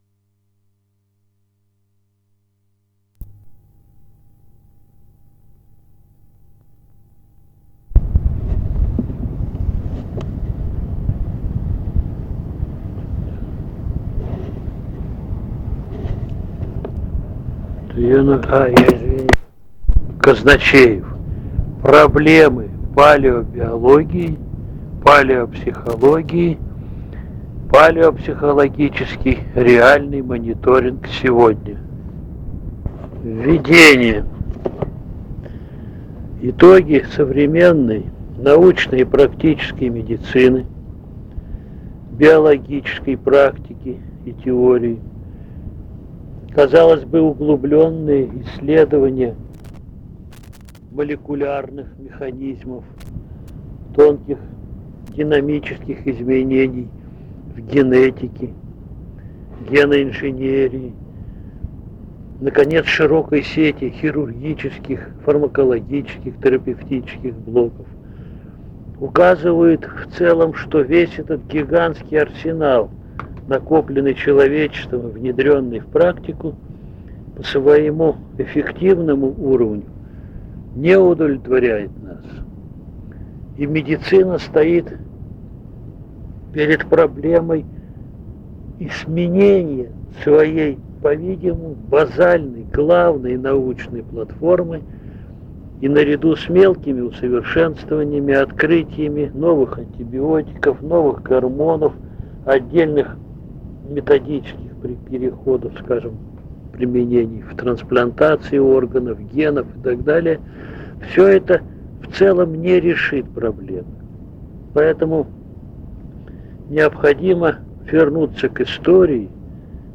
- Устная речь.